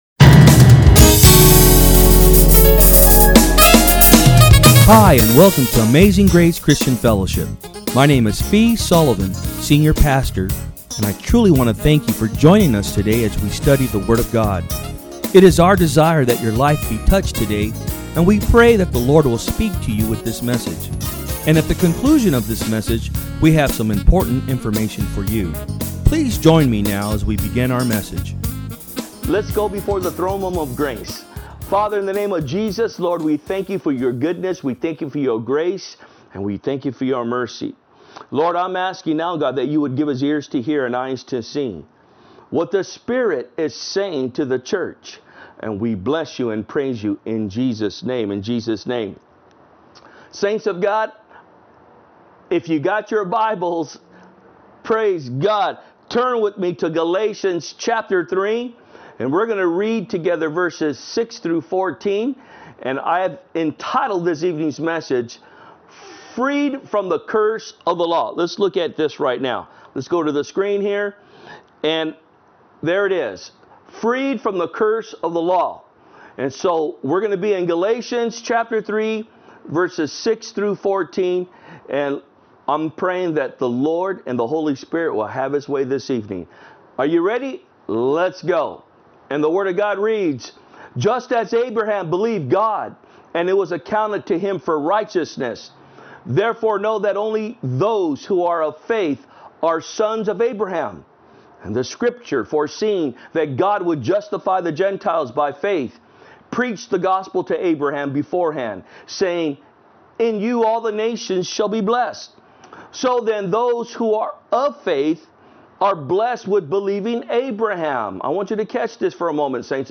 From Service: "Wednesday Pm"